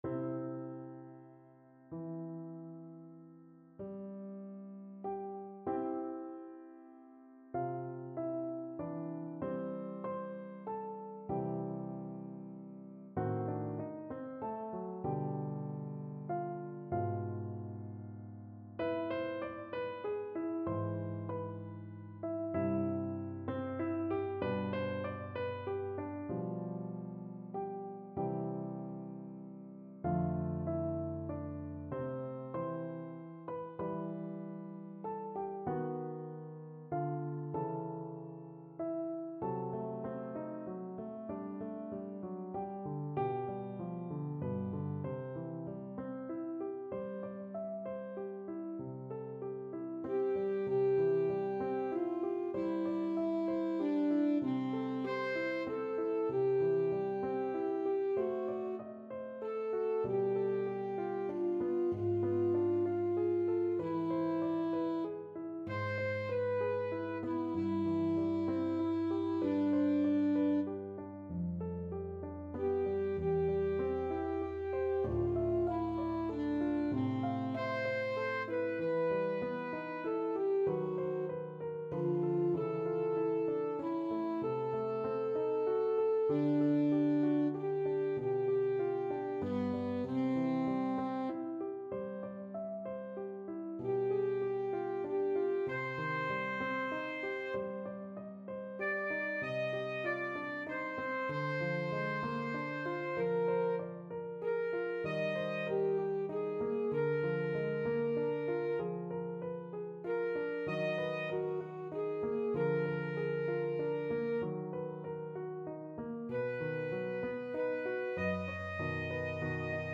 Alto Saxophone
9/8 (View more 9/8 Music)
Bb4-F#6
C major (Sounding Pitch) A major (Alto Saxophone in Eb) (View more C major Music for Saxophone )
Adagio un poco lento e dolce assai (=96)
Classical (View more Classical Saxophone Music)